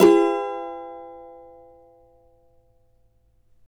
CAVA F#MN  U.wav